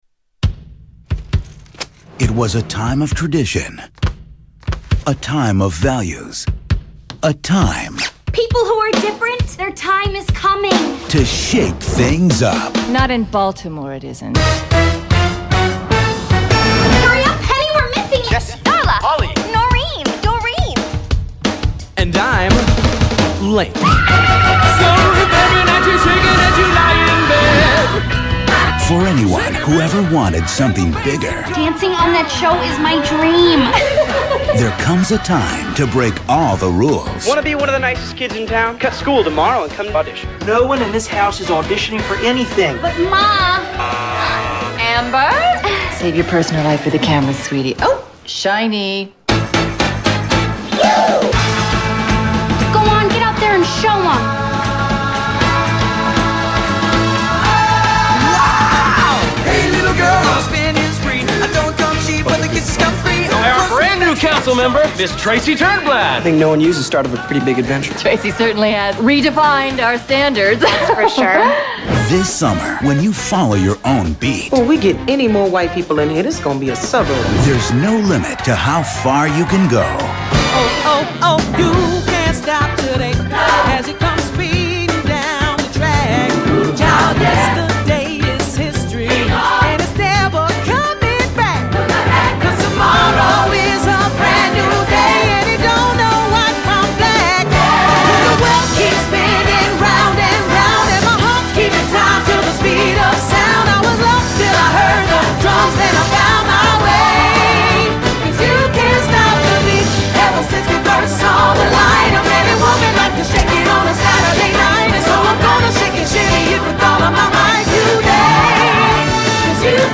Hairspray Movie Trailer
Tags: Movie Musical Hairspray Hairspray movie clips John Travolta